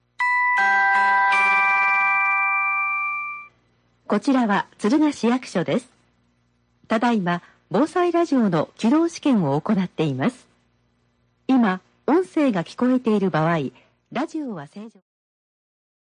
(サンプル)防災ラジオ音声データ